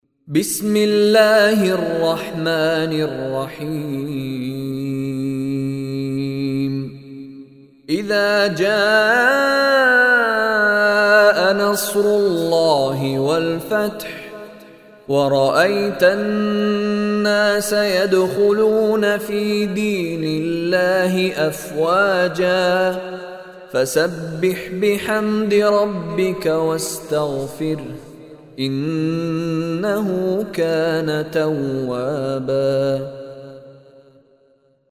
منتخب تلاوتهای شیخ مشاری العفاسی